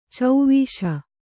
♪ cauvīśa